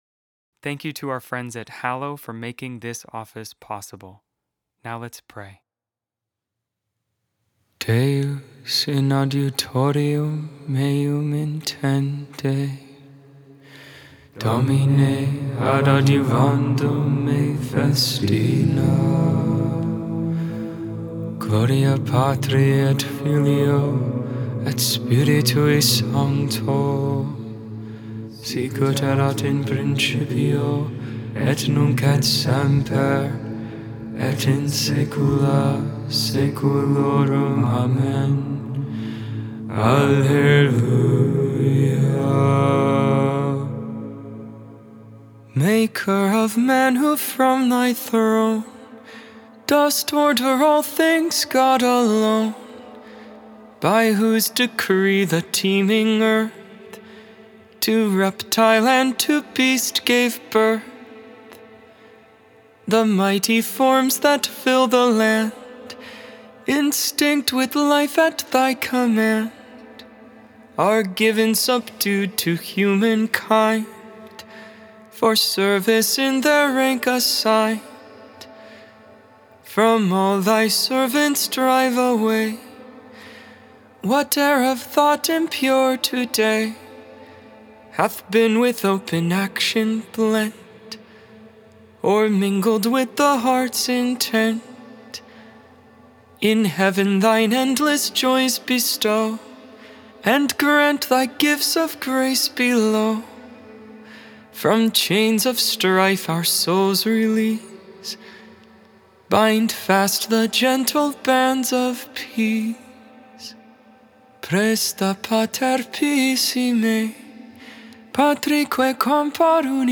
Made without AI. 100% human vocals, 100% real prayer.